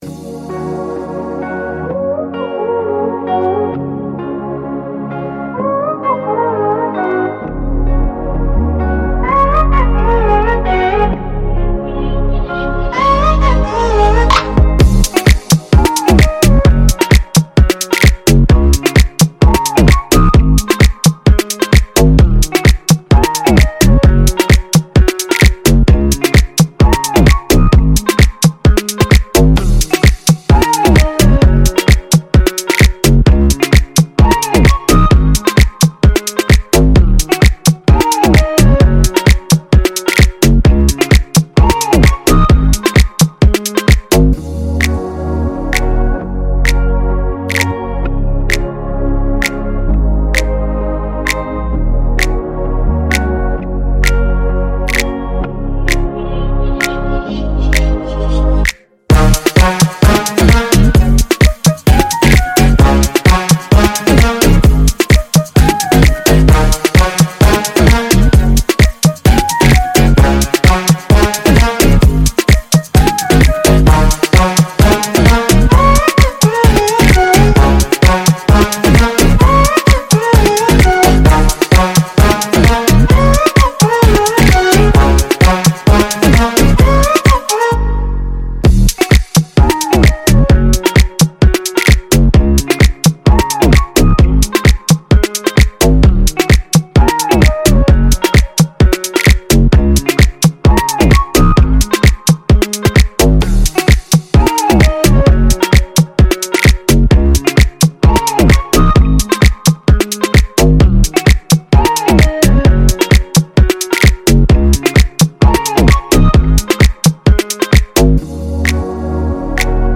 детской песни